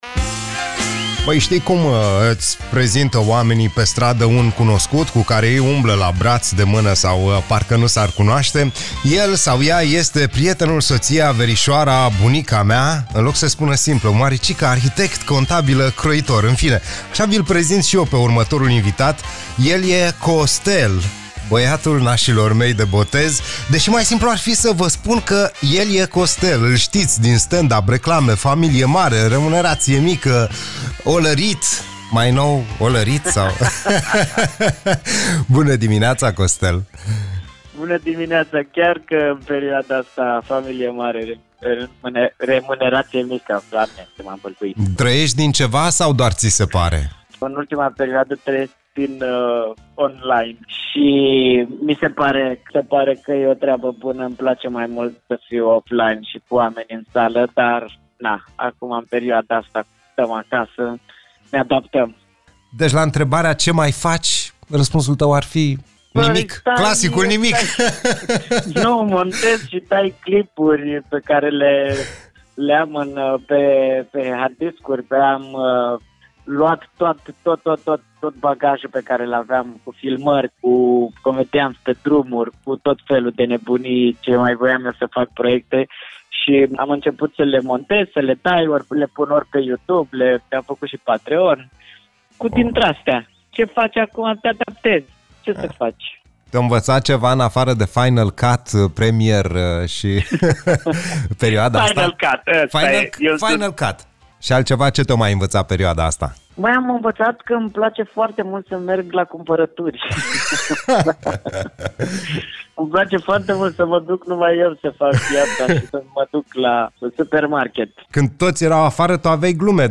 În Play the Day l-am deranjat cu un telefon pentru că în aceste vremuri, umorul trebuie împărțit și la el am găsit din plin.